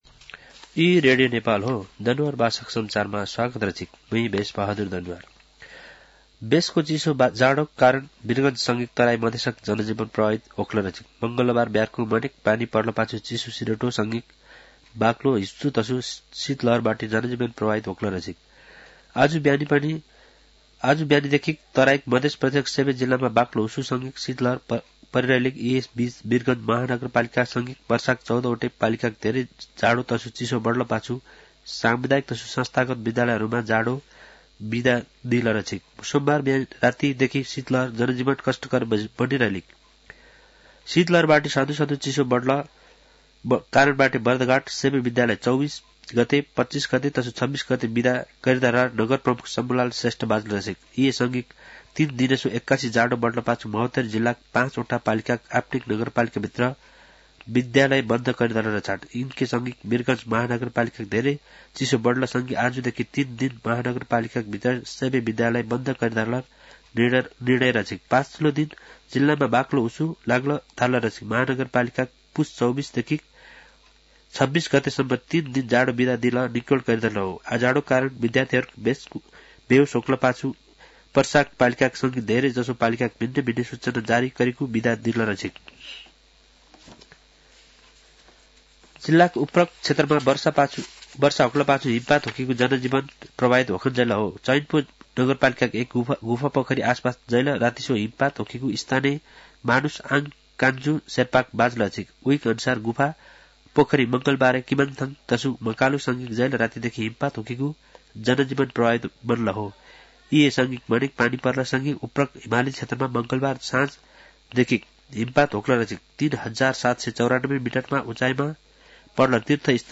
दनुवार भाषामा समाचार : २५ पुष , २०८१
Danuwar-news-.mp3